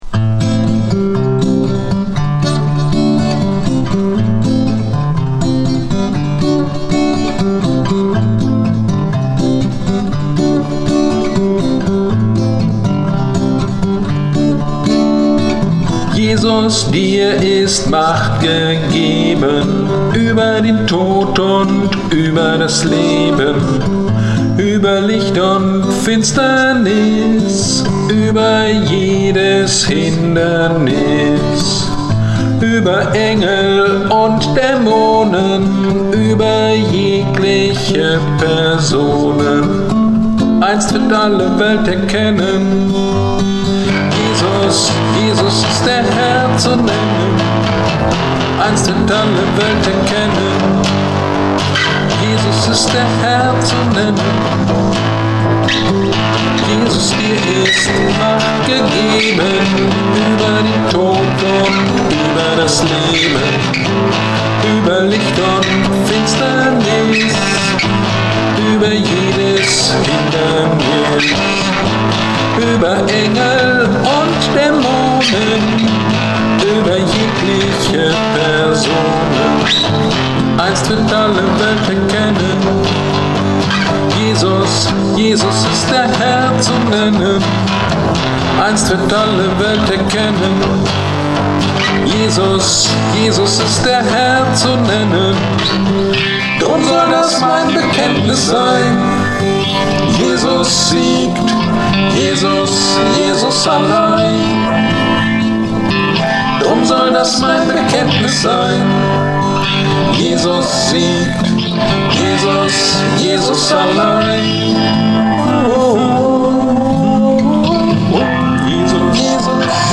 Predigt am 07.03.21 zu Lukas 11,14-18 - Kirchgemeinde Pölzig